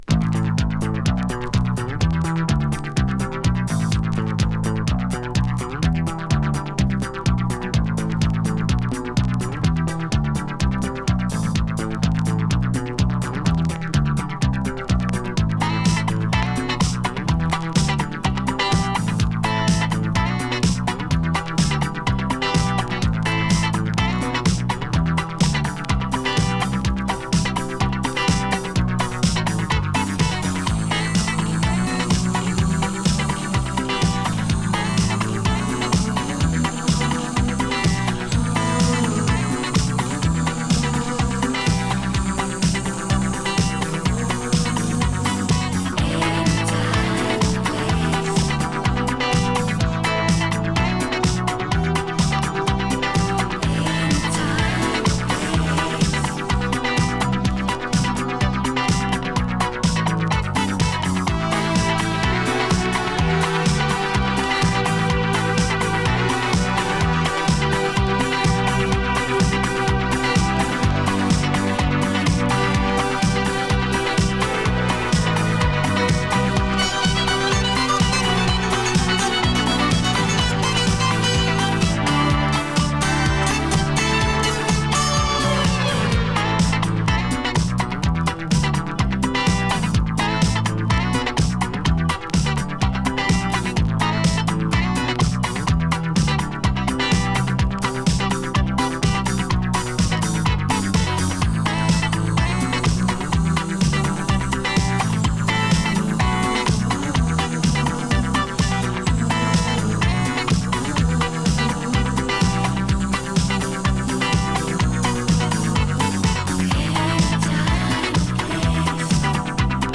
Italo Disco Classic！
Bサイドには、インスト・ヴァージョンを収録！